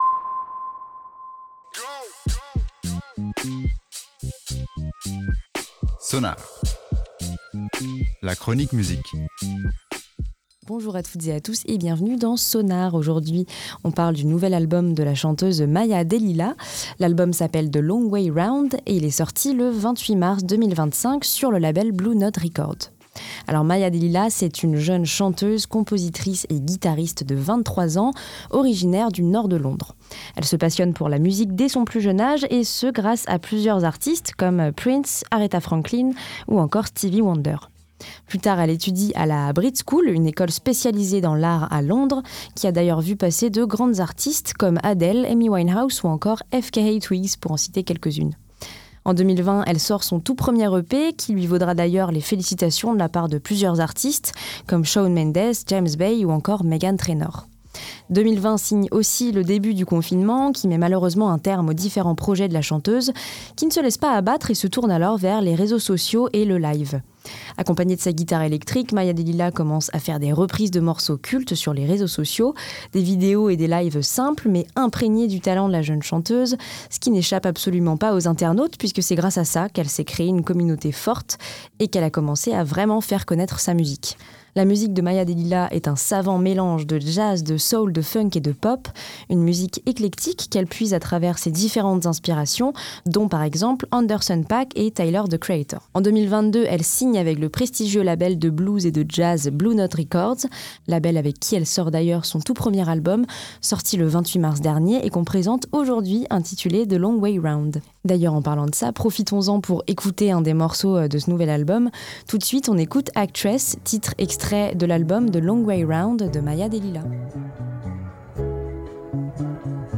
Un projet très éclectique de 12 titres mêlant pop, soul, funk et folk.